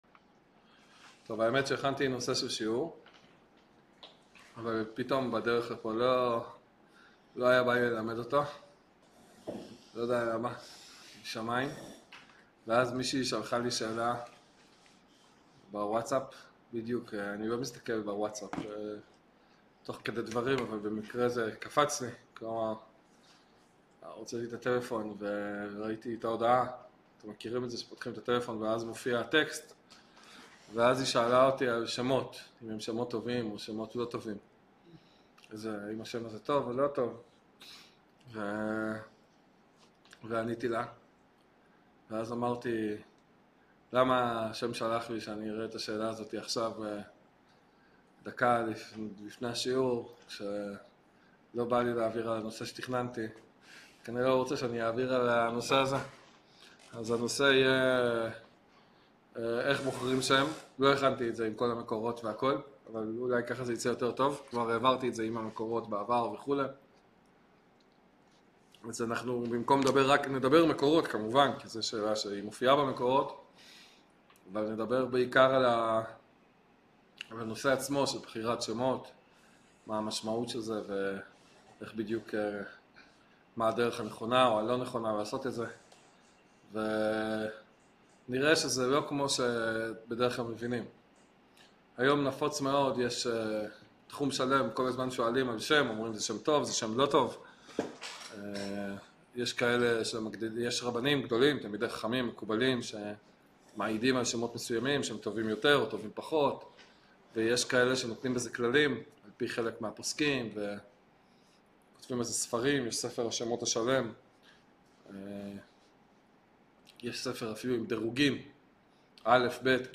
שיעור עמוק ומקיף על נושא מרתק יצירת תמונה